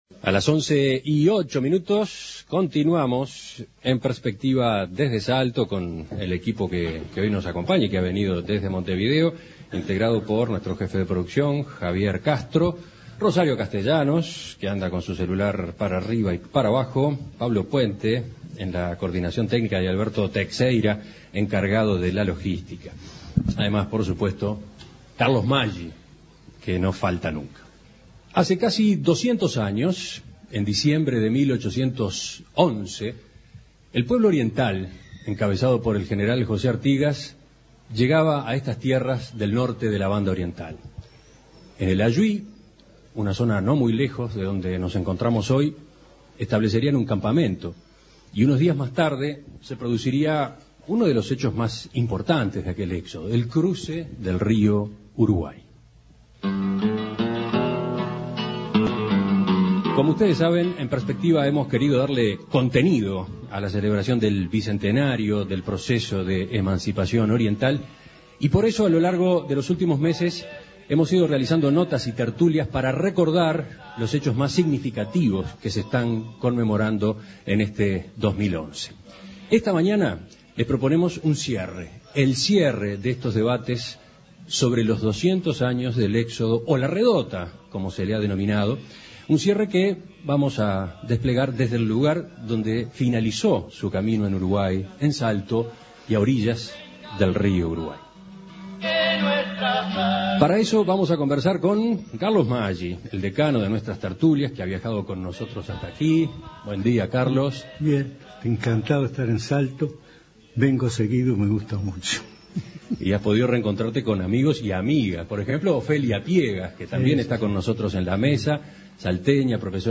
Mesa Redonda: El Éxodo llega al Ayuí (audio)
En Perspectiva realizó la última Tertulia especial dedicada al Éxodo del Pueblo Oriental y su importancia en la historia nacional. Desde el lugar donde, hace 200 años, los orientales, encabezados por José Artigas, cruzaron el Río Uruguay, dejando atrás la Banda Oriental, participaron en la charla